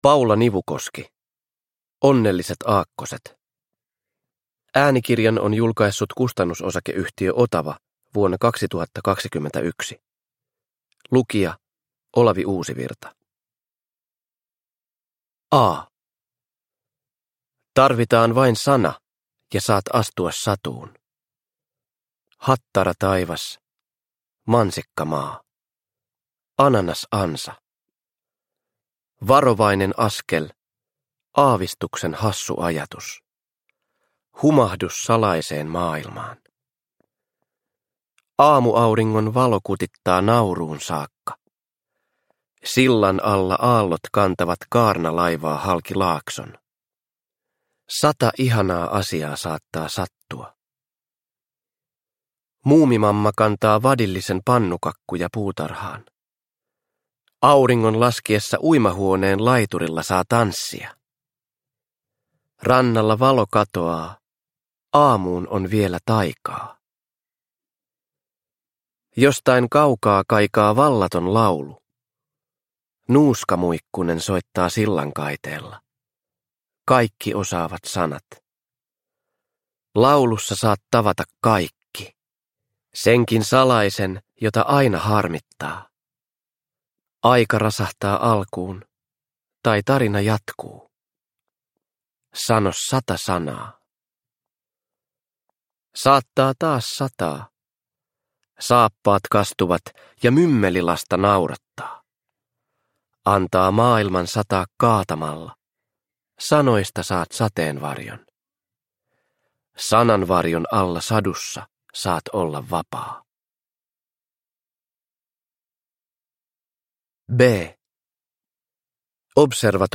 Onnelliset aakkoset – Ljudbok – Laddas ner
Uppläsare: Olavi Uusivirta